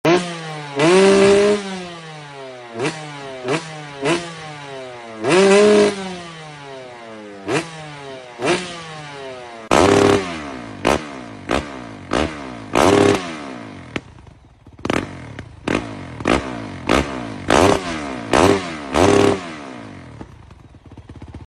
2stroke Or 4stroke ? #2stroke Sound Effects Free Download